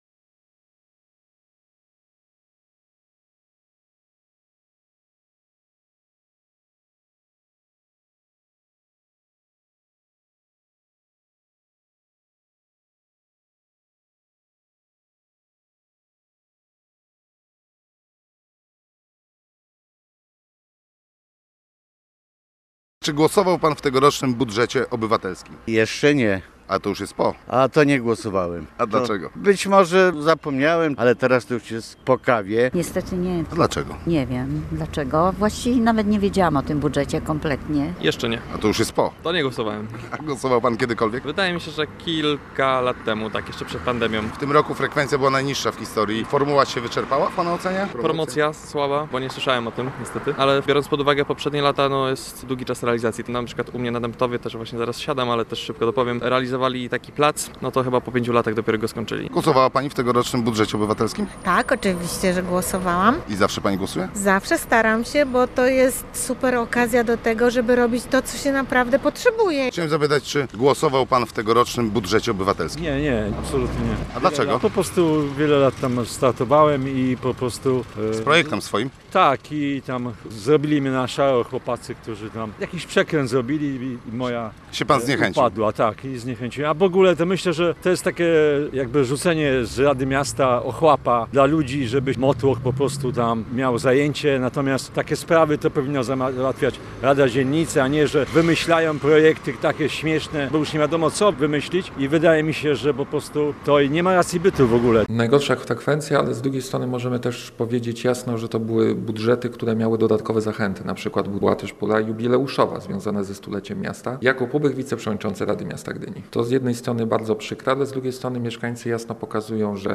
Gdynia chce zmienić formułę budżetu obywatelskiego. O pomysłach mówiliśmy na naszej antenie: